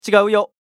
男性ボイス | 無料 BGM・効果音のフリー音源素材 | Springin’ Sound Stock
ちがうよ1.mp3